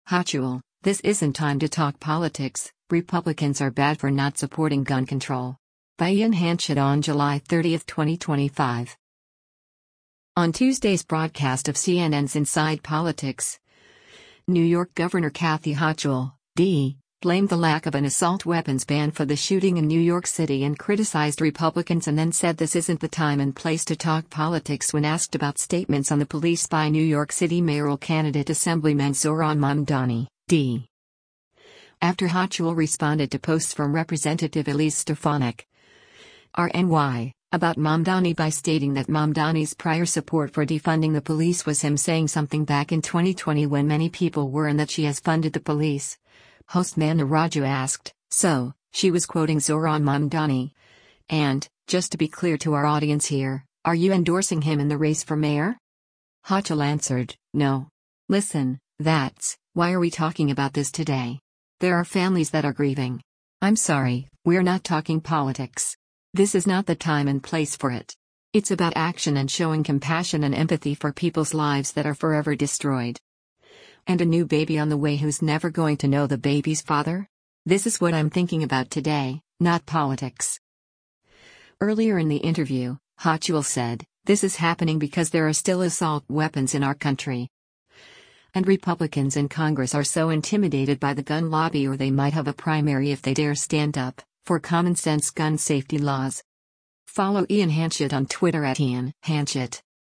On Tuesday’s broadcast of CNN’s “Inside Politics,” New York Gov. Kathy Hochul (D) blamed the lack of an assault weapons ban for the shooting in New York City and criticized Republicans and then said this isn’t the time and place to talk politics when asked about statements on the police by New York City mayoral candidate Assemblyman Zohran Mamdani (D).